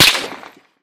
light_crack_01.ogg